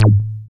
Bass (1).wav